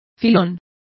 Complete with pronunciation of the translation of vein.